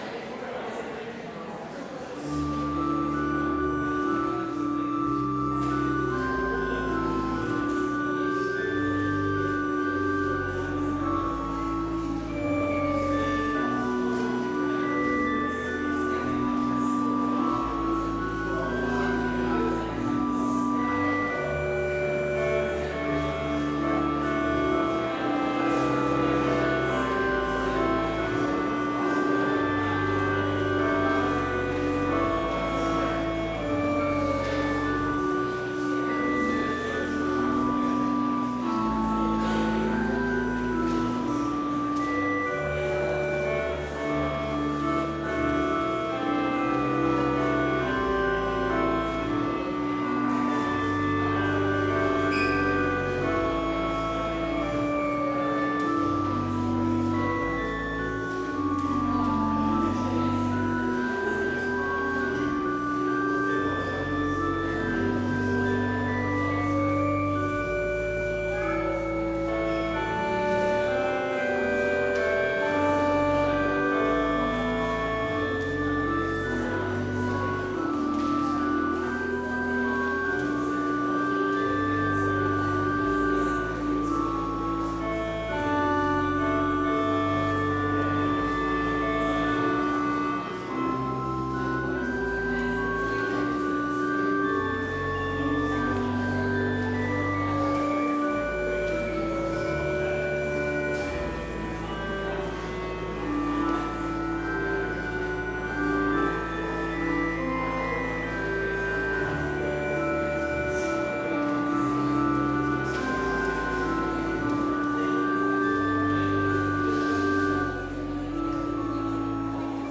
Chapel service in Bethany Lutheran College's chapel
Complete service audio for Chapel - February 16, 2022